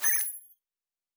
pgs/Assets/Audio/Sci-Fi Sounds/Weapons/Additional Weapon Sounds 4_2.wav at master
Additional Weapon Sounds 4_2.wav